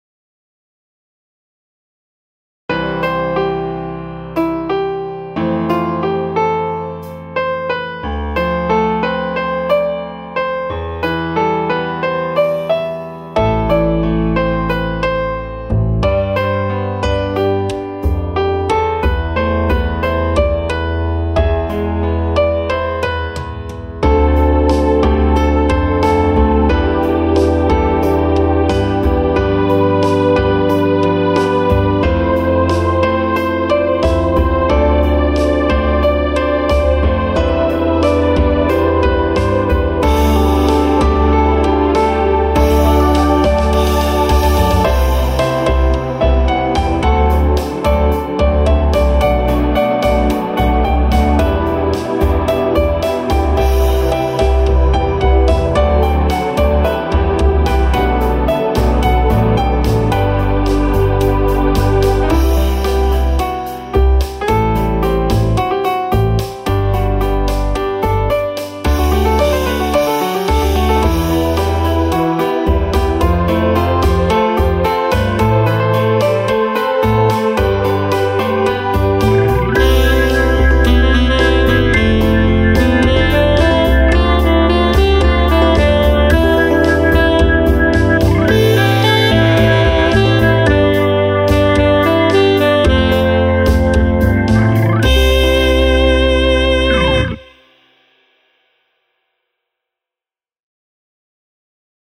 ショートスローテンポ